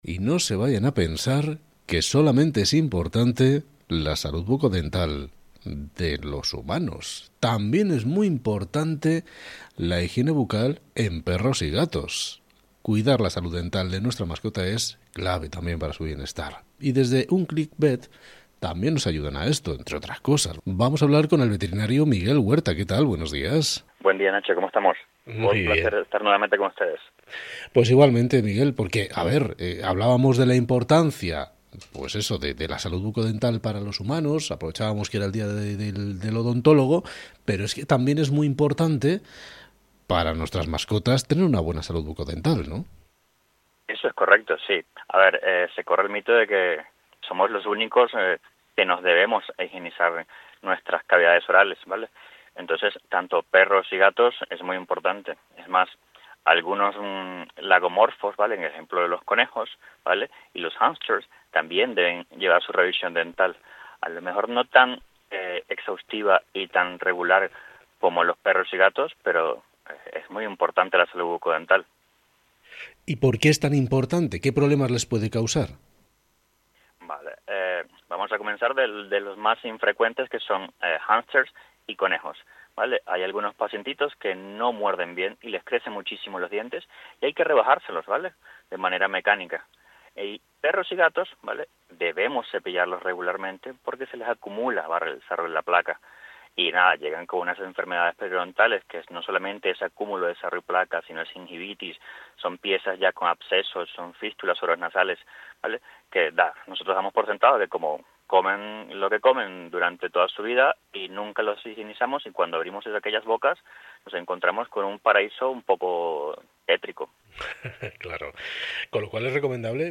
Entrevista en radio: Cuidar la salud dental de tu mascota es clave para su bienestar
Entrevista-Cadena-Ser-Click-Vet-higiene-bucodental-perros-y-gatos.mp3